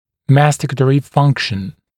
[‘mæstɪkətərɪ ‘fʌŋkʃn][‘мэстикэтэри ‘фанкшн]жевательная функция